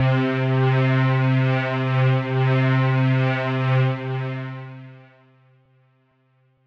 Lush Pad 2 C4.wav